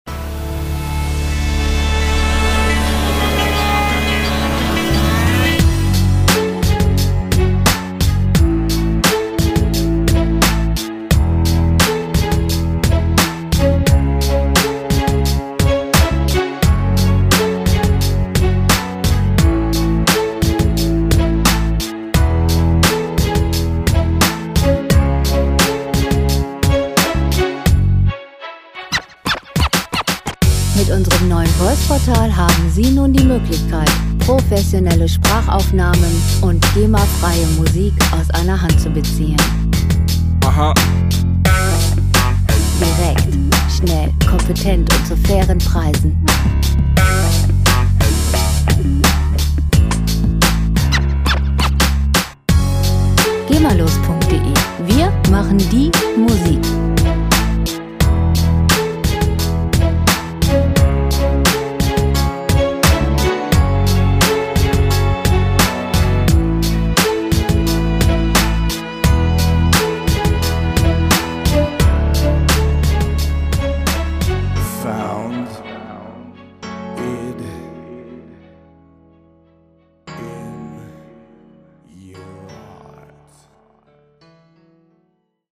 Lounge Musik - Romanzen
Musikstil: Hip-Hop
Tempo: 87 bpm
Tonart: Fis-Moll
Charakter: traurig, tiefsinnig